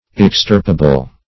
Extirpable \Ex*tir"pa*ble\, a. Capable of being extirpated or eradicated; as, an extirpable plant.